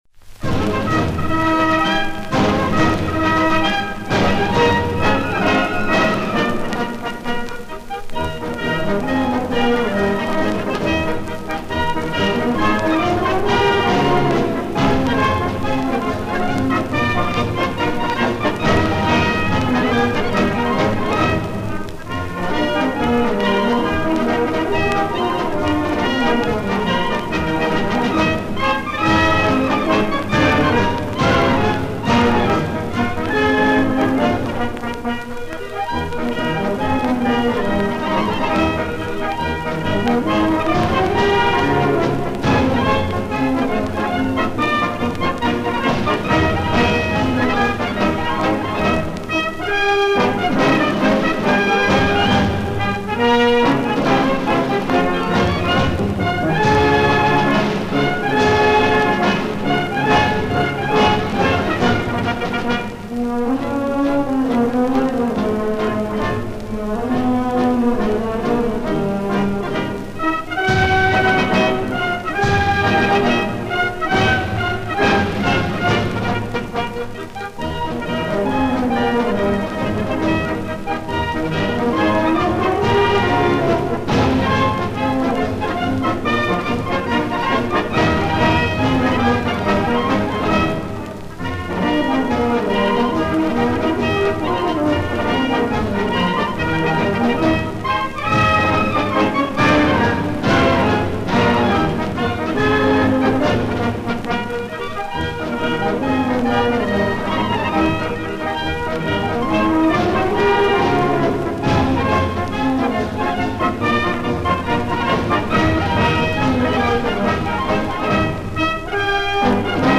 редкая пластинка